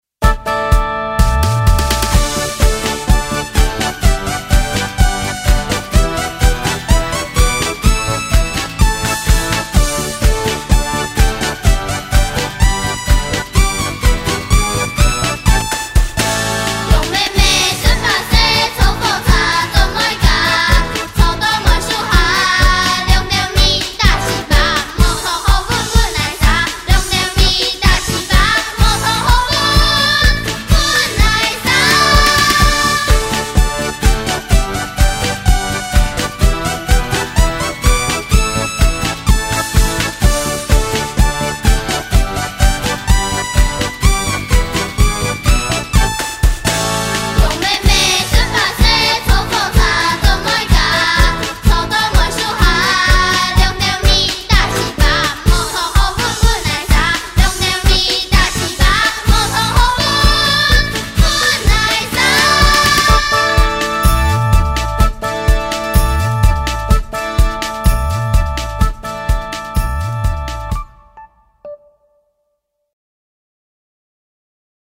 羊咩咩(完整演唱版) | 新北市客家文化典藏資料庫